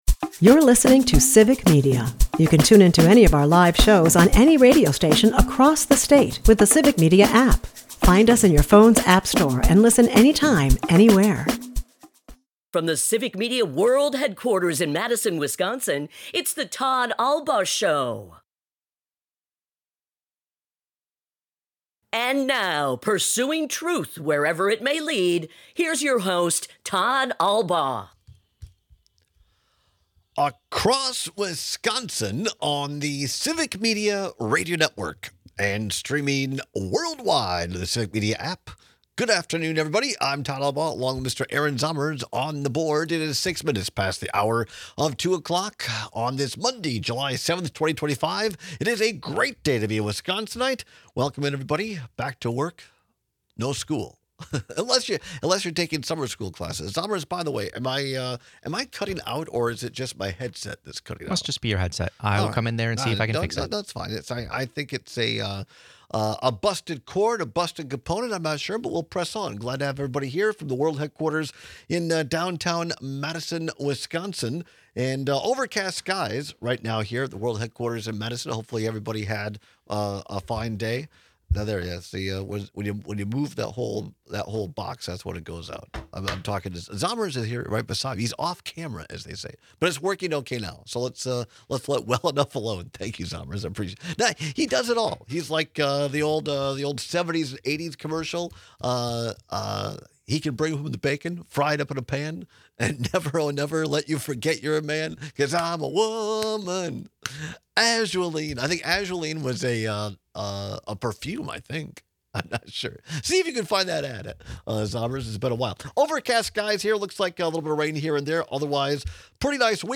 She provides her perspective on these acts of vandalism before taking your calls on our new state budget.